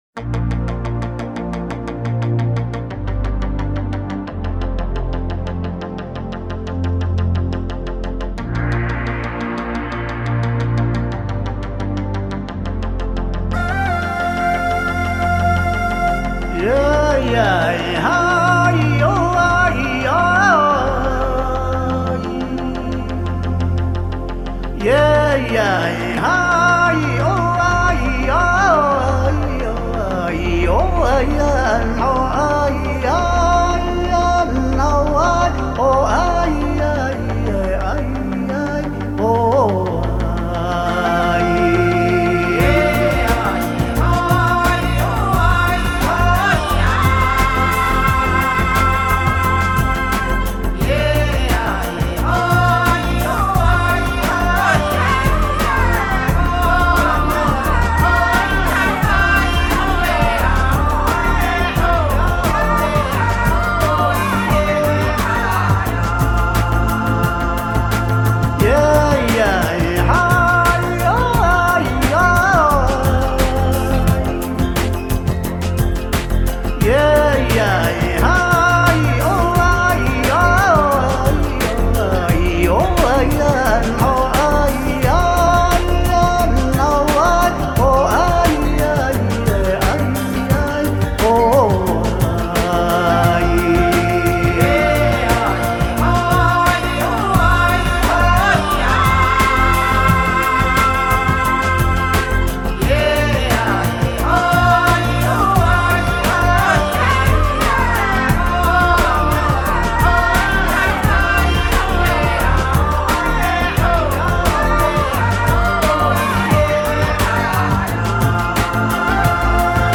difang-elders-drinking-song.mp3